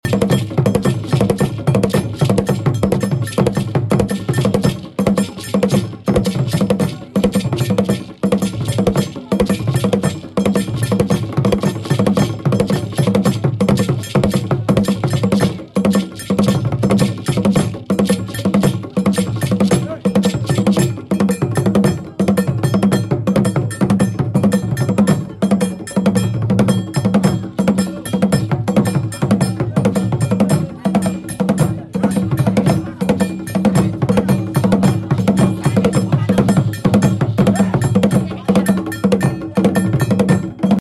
Asante cultural dance displayed astounding